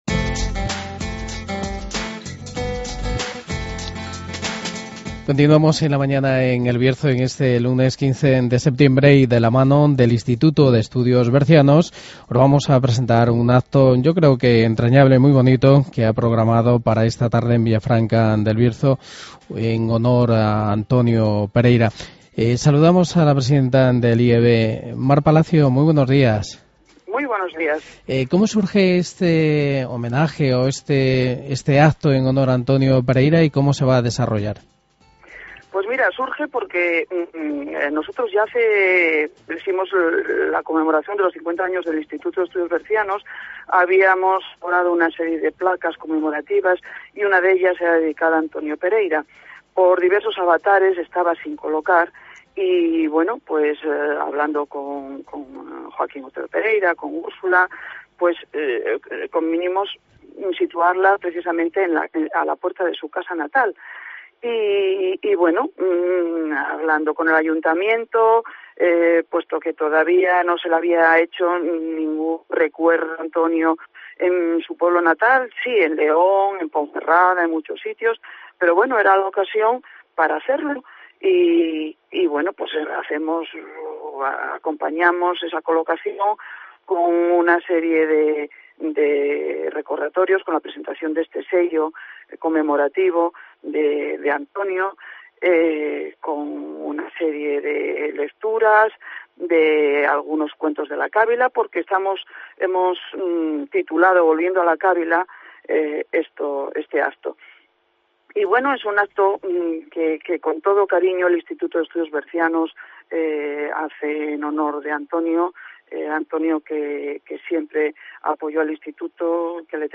Entrevista en la COPE